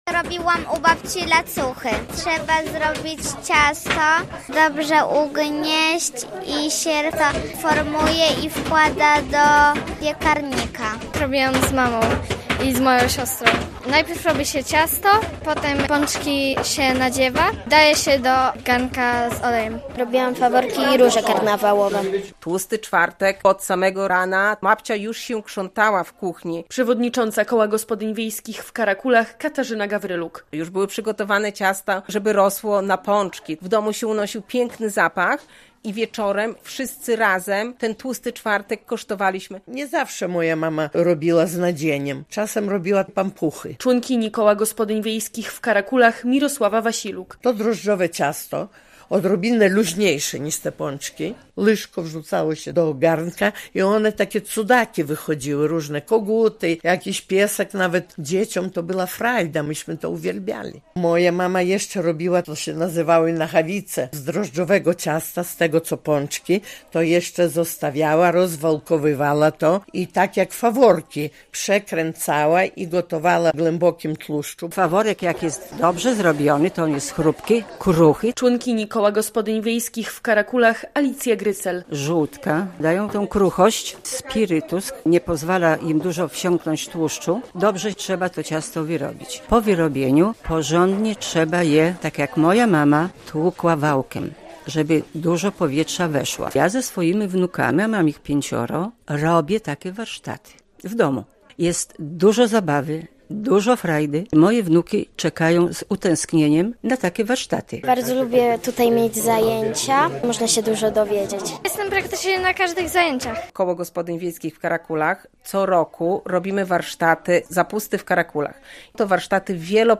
Jak kiedyś obchodzono tłusty czwartek? Koło Gospodyń Wiejskich w Karakulach zorganizowało warsztaty - relacja
A panie z koła przy okazji spotkania wspominały wypieki ze swojego dzieciństwa.